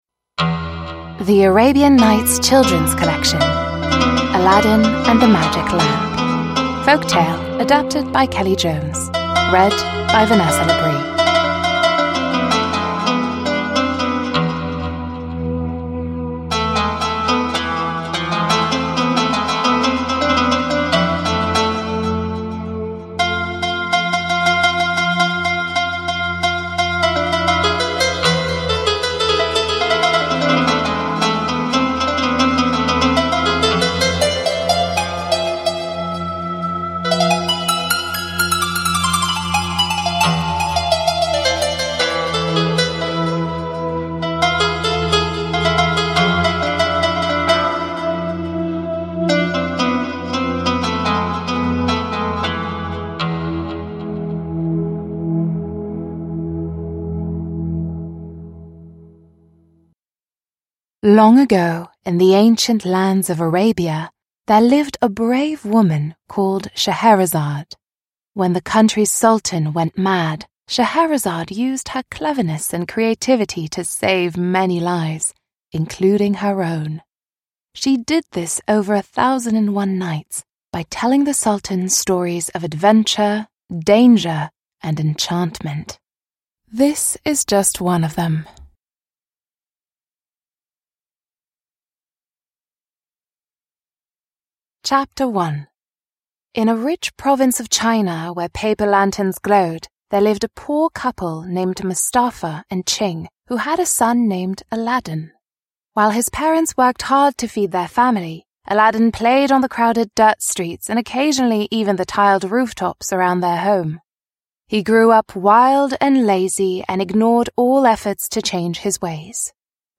• Ljudbok